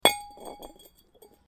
Звук крышки от бидона на столе